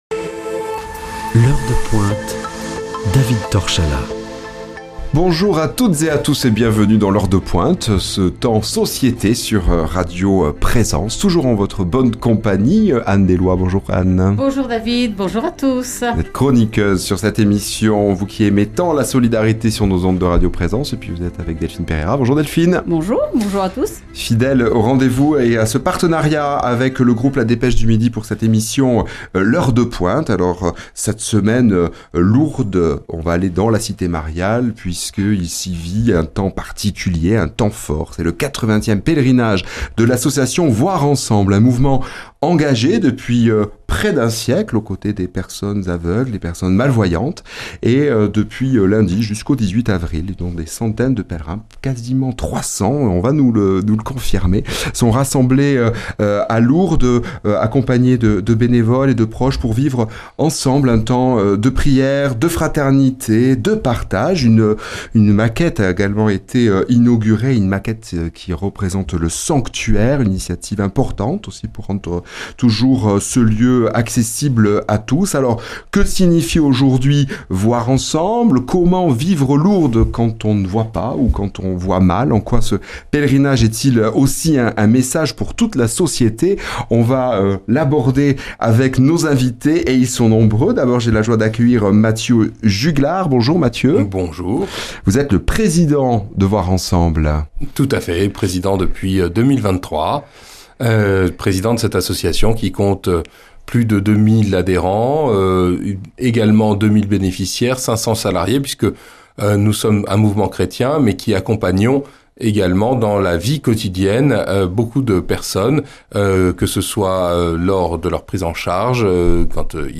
Dans cette émission de "L’Heure de Pointe", direction Lourdes pour un moment fort d’humanité et de fraternité avec le pèlerinage de l’association "Voir Ensemble".